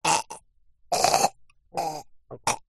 Звуки человека
Мужчина задыхается